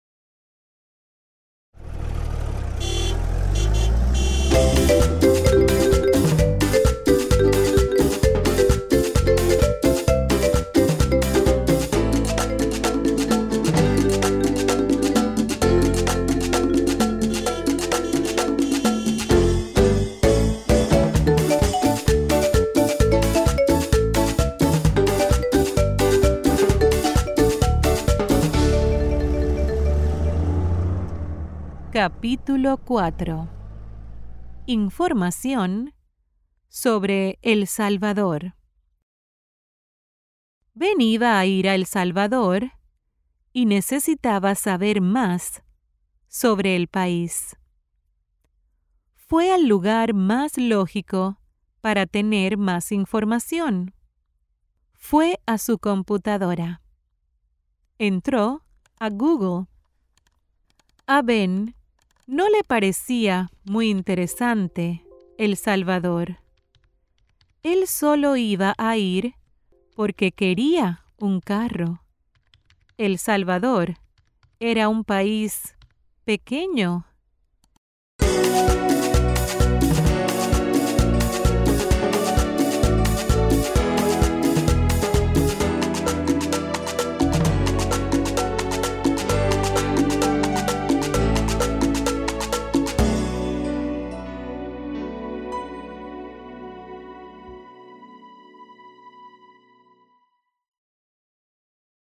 Each audiobook contains original music, sound effects and voice acting from Native Spanish speakers to ensure a memorable experience for your students!
Mi-propio-auto-past-capitulo-4-sample.mp3